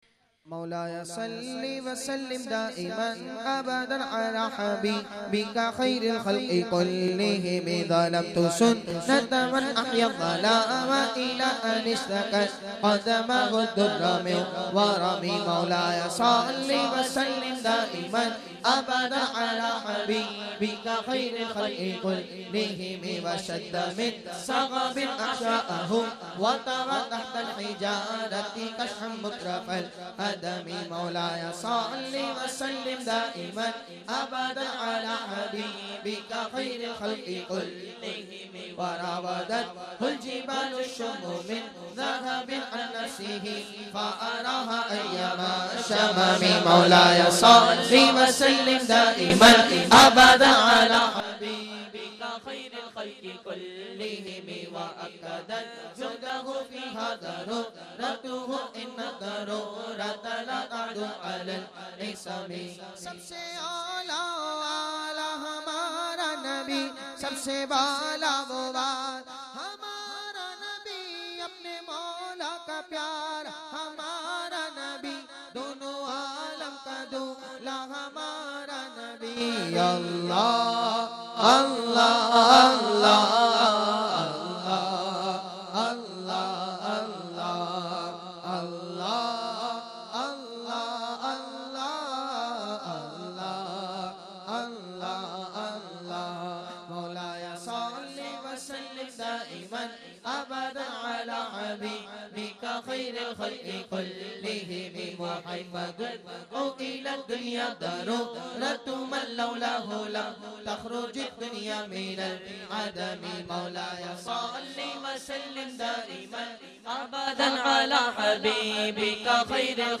recited by famous Naat Khawan